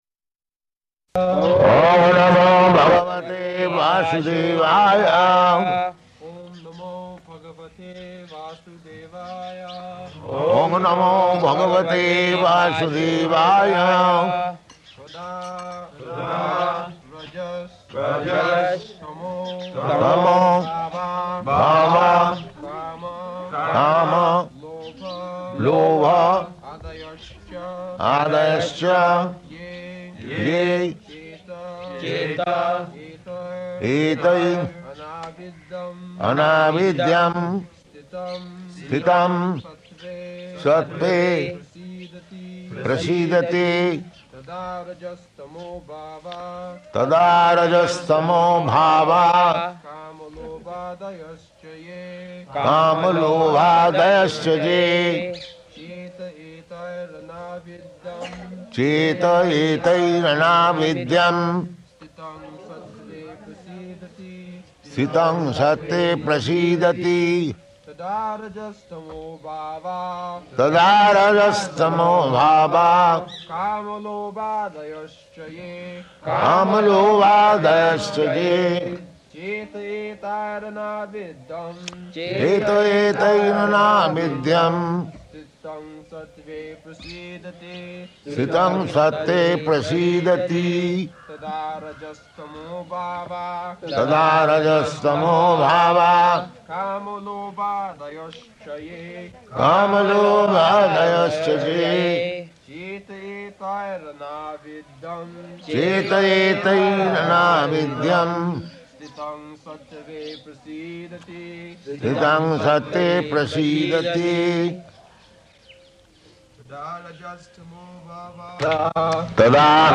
October 30th 1972 Location: Vṛndāvana Audio file
[leads chanting of verse, etc.] [Prabhupāda and devotees repeat]